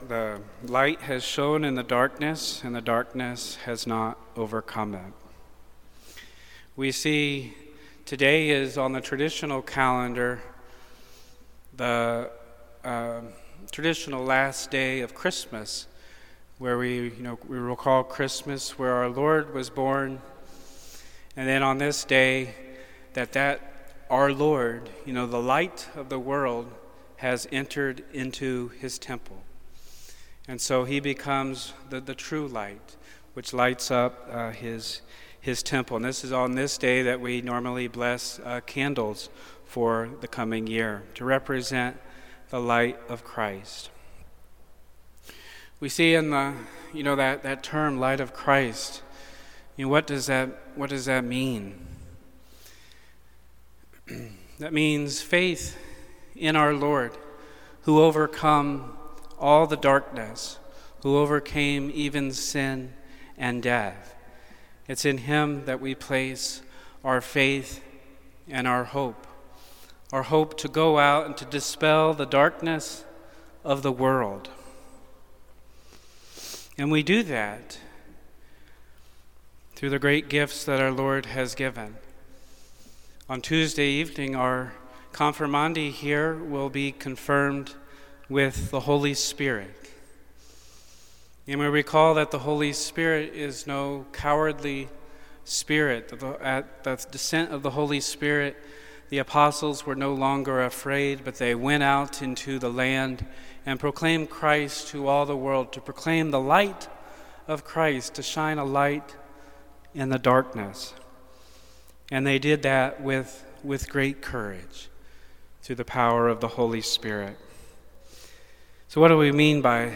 Homilies - All Saints Catholic Church